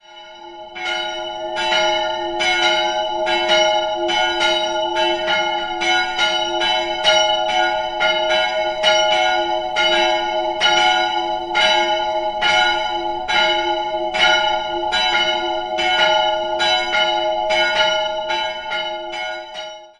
2-stimmiges Geläute: f''-fis'' (verengt) Die Glocken dürften (Angaben ohne Gewähr!) beide aus der Gießhütte der Nürnberger Familie Glockengießer stammen. Die größere wurde Ende des 15. Jahrhunderts, die kleinere im 16. Jahrhundert gegossen.